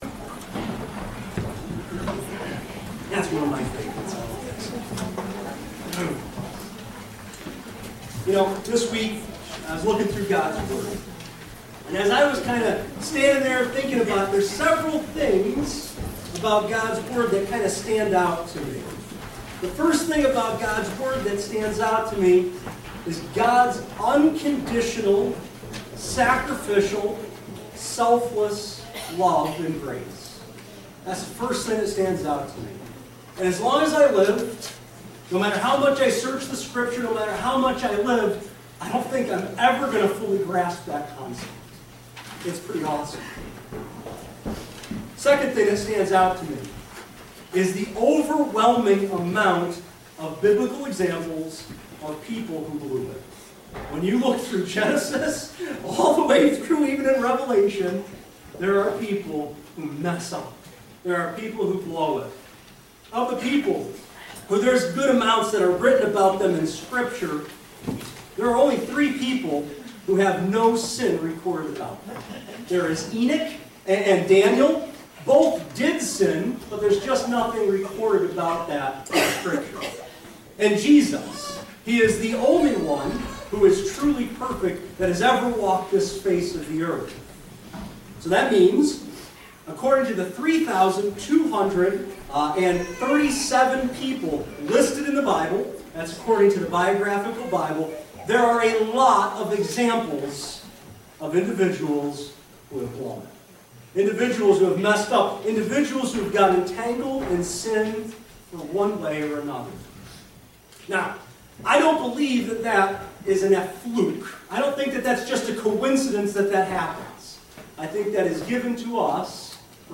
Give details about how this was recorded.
Sermons at Calvary Bible Church in Wevertown, NY